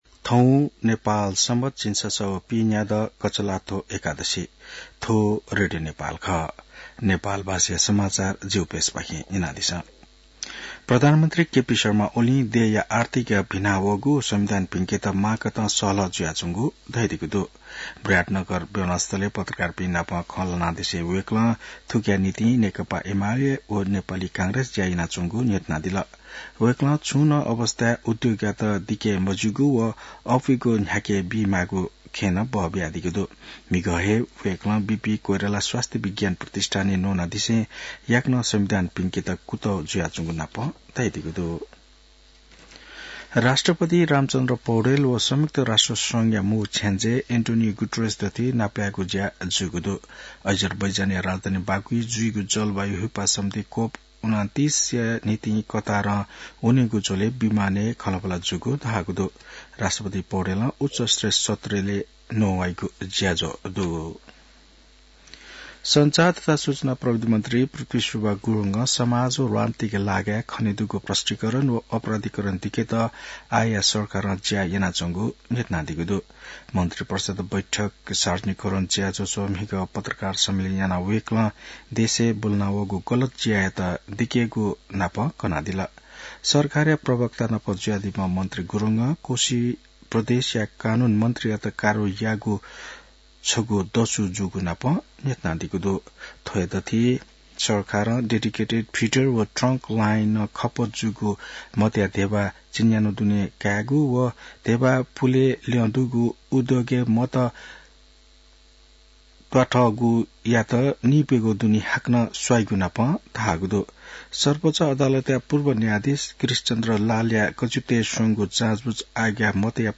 नेपाल भाषामा समाचार : २८ कार्तिक , २०८१